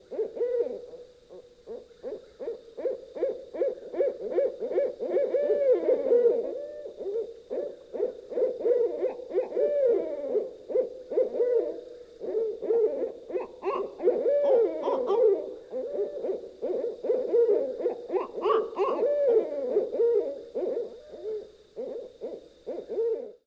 hoot-sound